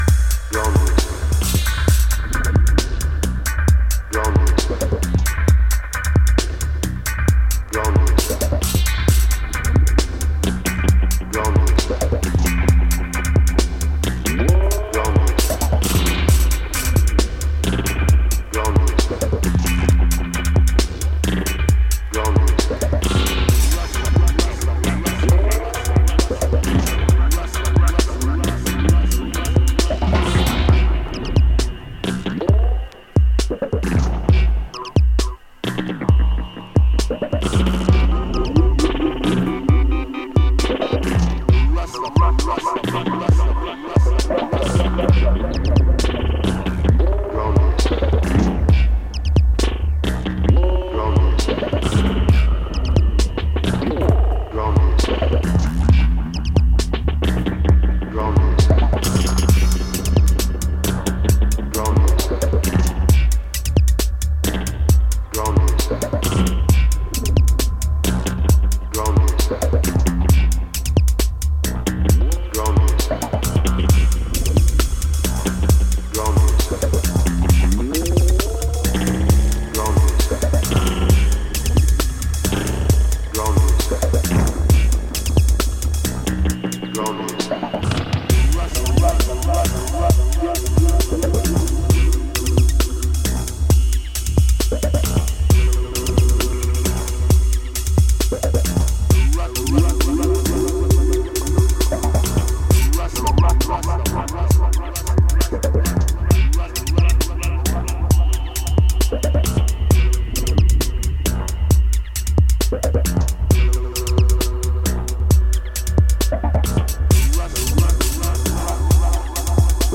サイケなヴォイスチョップなどでじわじわと音数を増やしてテンションを高める133BPMミニマル・ポスト・ダブステップ